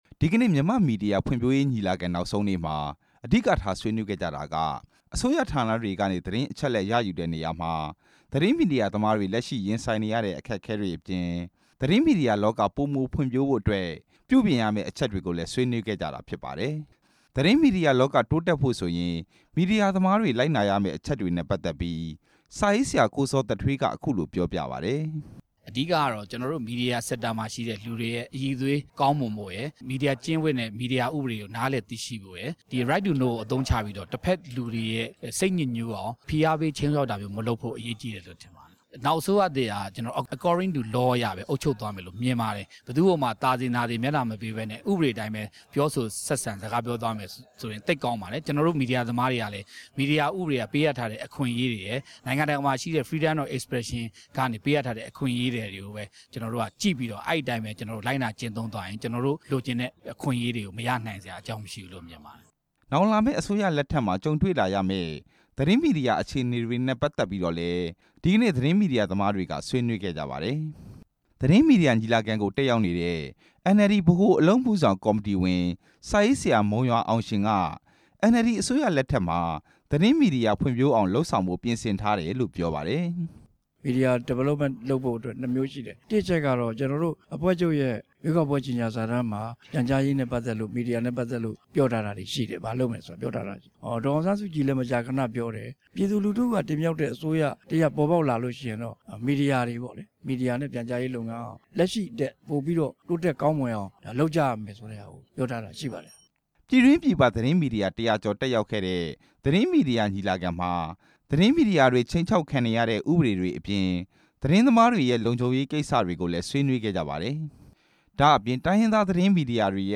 ရန်ကုန်မြို့ ချက်ထရီယံတယ်မှာ ကျင်းပတဲ့ မြန်မာမီဒီယာ ဖွံ့ဖြိုးတိုးတက်ရေး ညီလာခံမှာ NLD ဗဟိုအလုပ် မှုဆောင်ကော်မတီဝင် စာရေးဆရာ မုံရွာအောင်ရှင် က RFA ကို အခုလိုပြောခဲ့တာဖြစ်ပါတယ်။